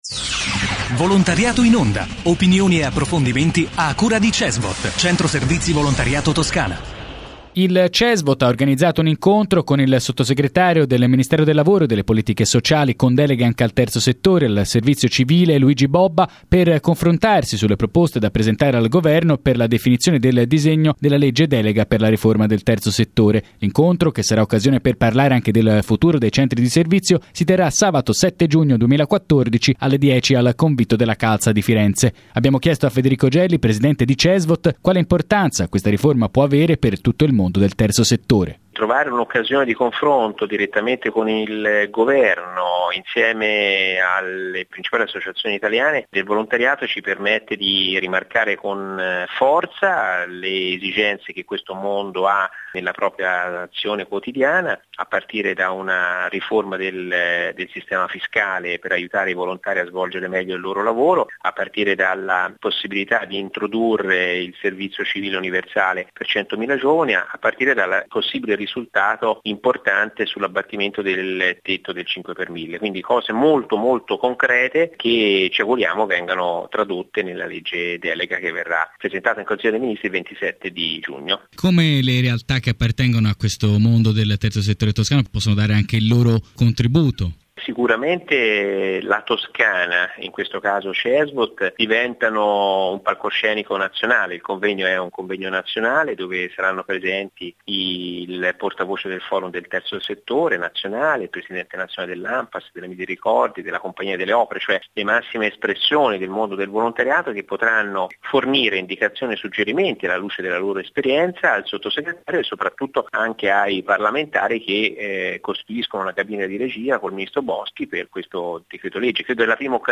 Intervista a Federico Gelli, presidente di Cesvot